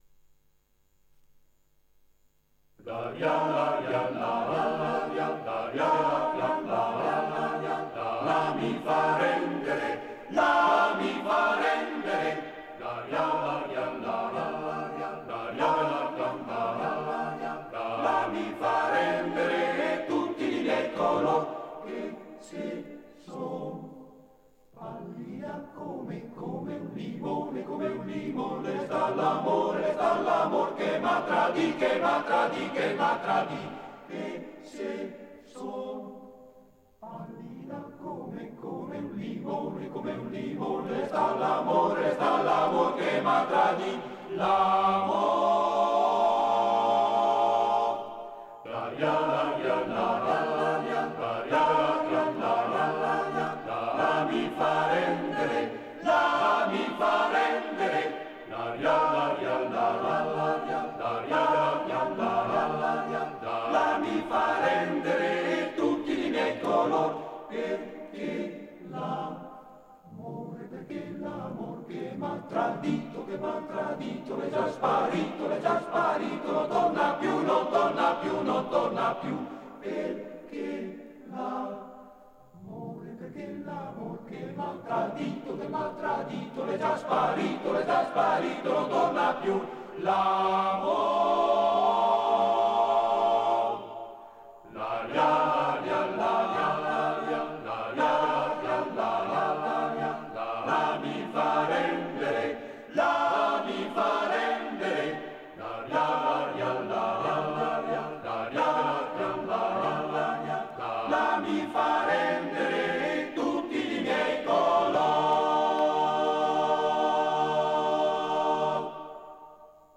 Arrangiatore: Pigarelli, Luigi
Esecutore: Coro della SAT